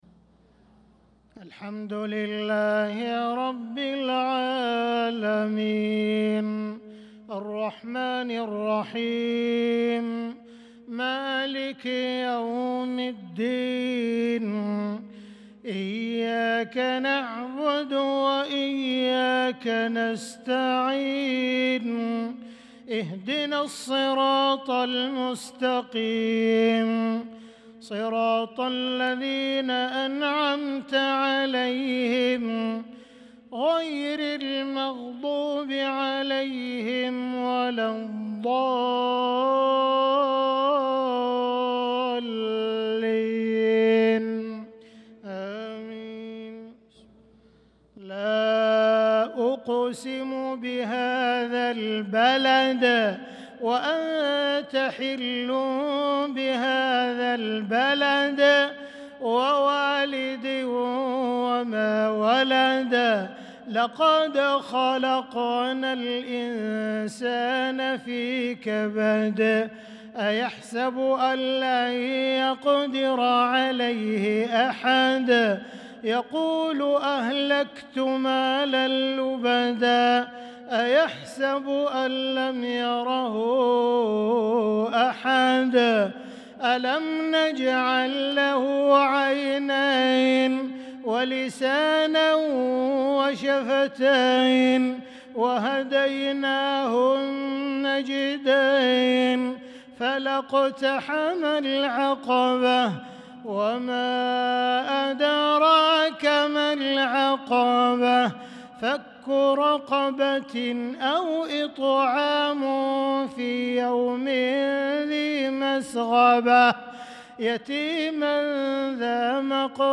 صلاة العشاء للقارئ عبدالرحمن السديس 22 شوال 1445 هـ